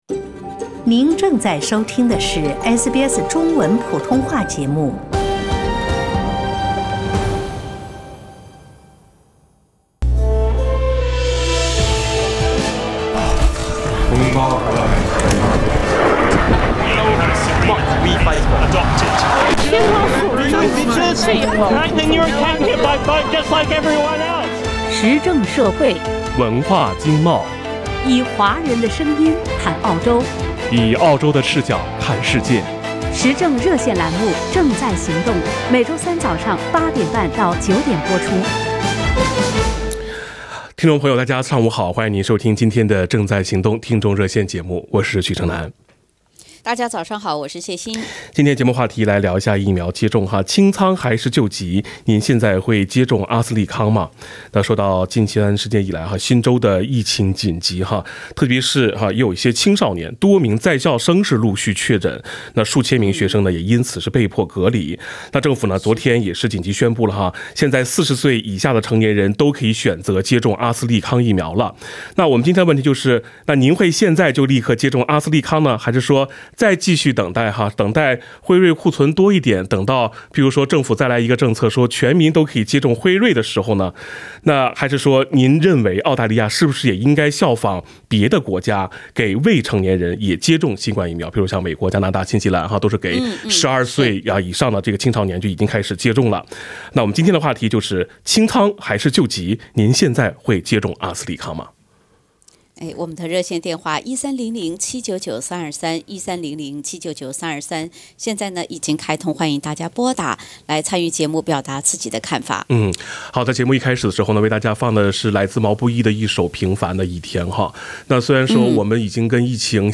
（点击封面图片，收听完整热线节目）